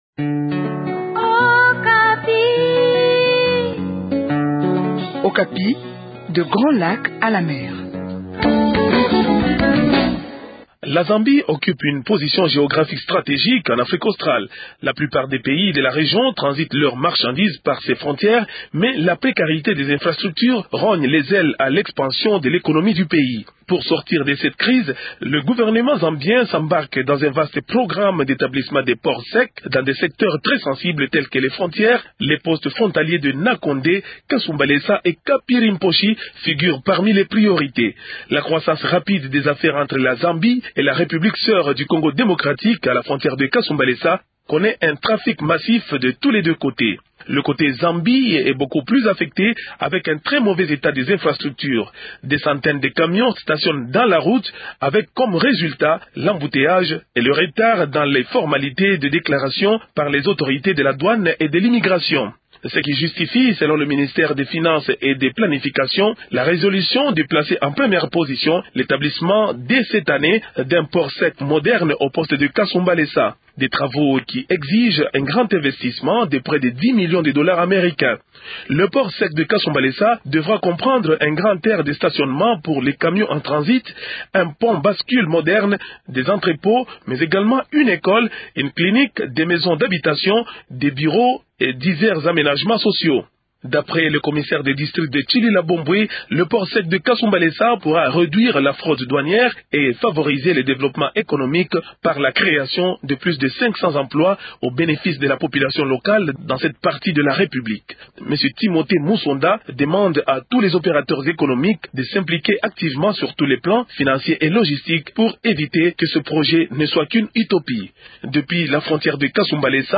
depuis la frontière de Kasumbalesa.